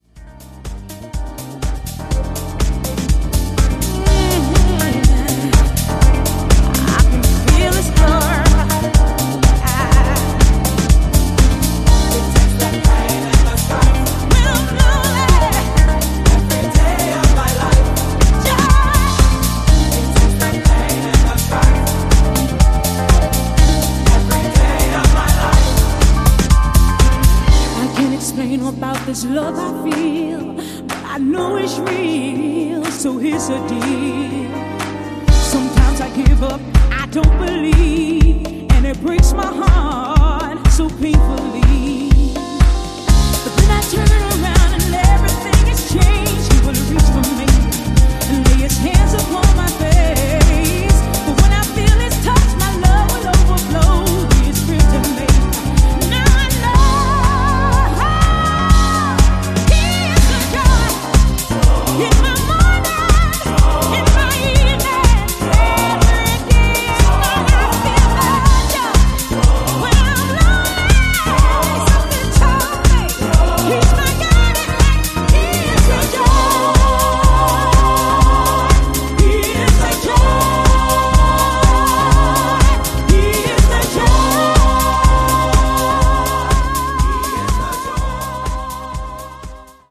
vocal-led, groove-driven house music